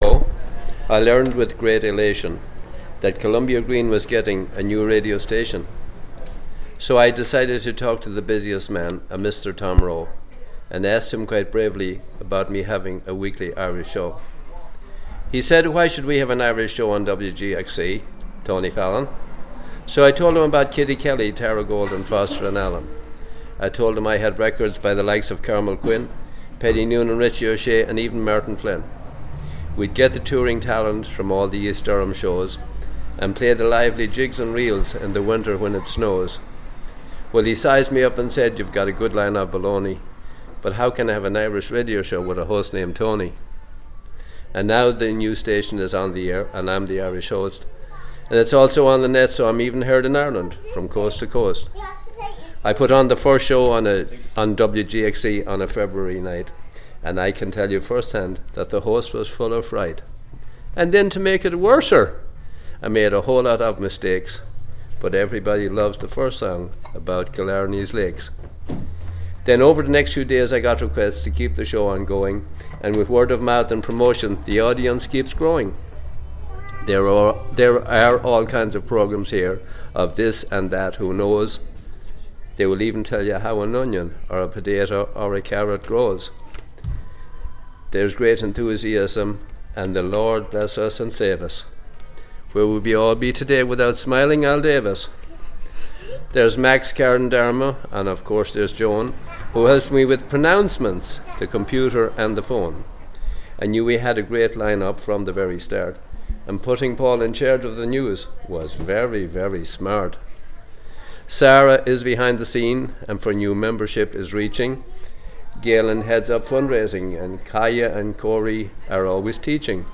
Recorded at WGXC Catskill Studio Groundbreaking.